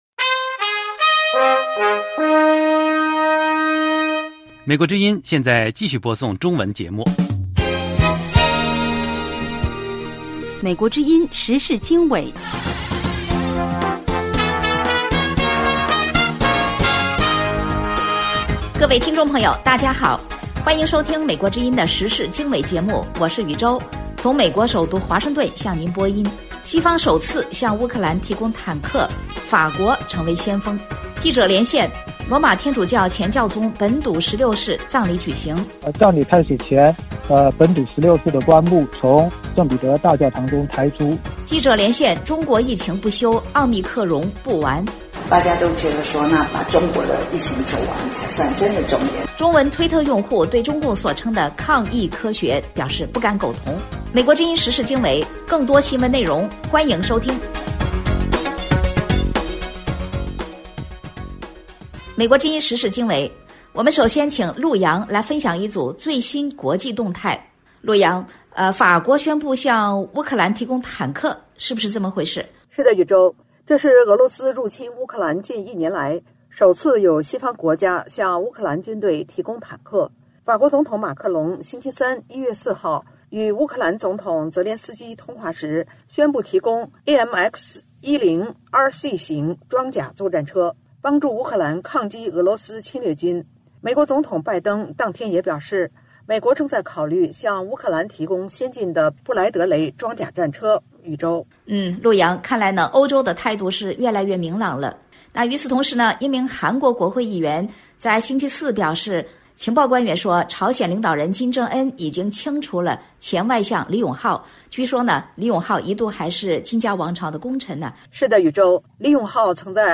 时事经纬(2023年1月6日)：1/西方首次向乌克兰提供坦克，法国担任先锋。2/记者连线：罗马天主教前教宗本笃十六世葬礼举行。3/记者连线：中国疫情不休，奥密克戎不完。4/中文推特用户对中共所称的“抗疫科学”，表示不敢苟同。